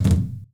TC3Perc2.wav